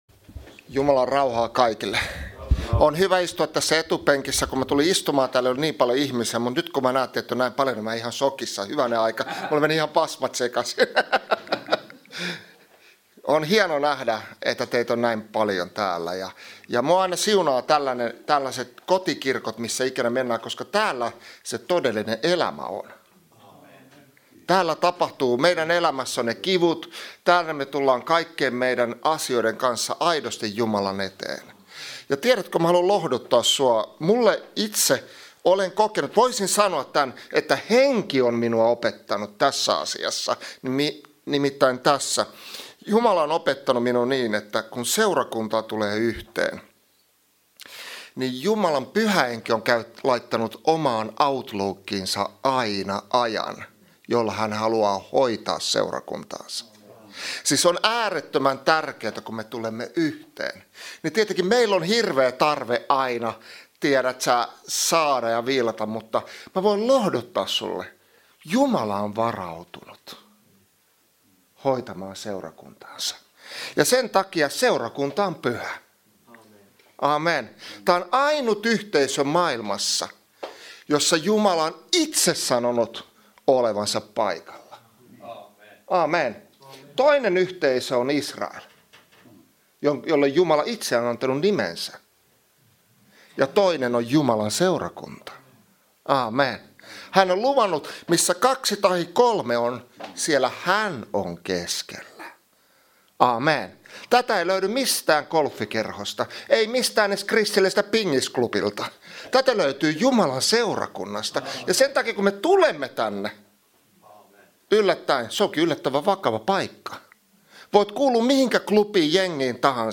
Vantaan Kotikirkko - Kuuntele puheita netissä
Service Type: Jumalanpalvelus